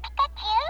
This is the sound of the little electric Pikachu toy.
toypika.wav